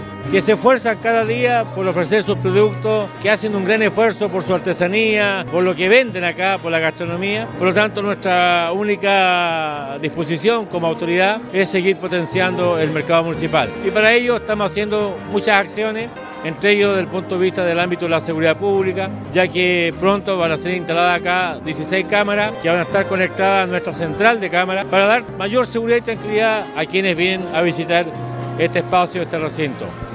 Durante la celebración, el jefe comunal, Emeterio Carrillo, anunció la pronta instalación de sistemas de televigilancia en distintos puntos y pasillos del lugar.